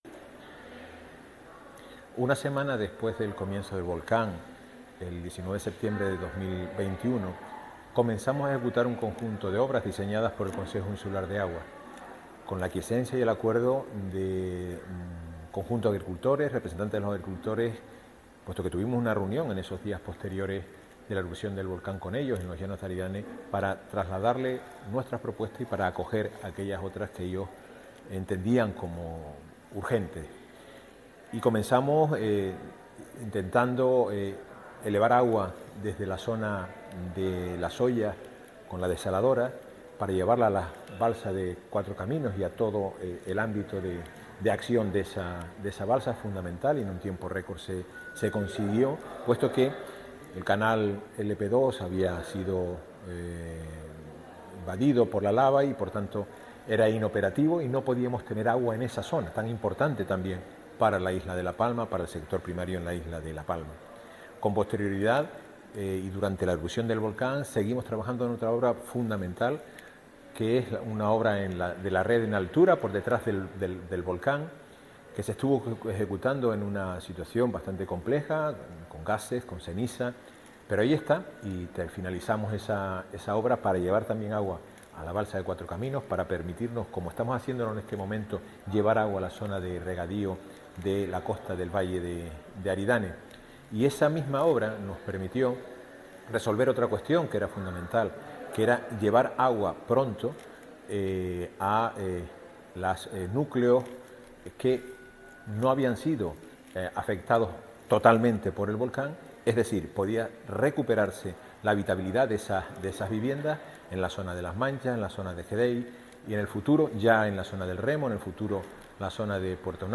Declaraciones audio Carlos Cabrera.mp3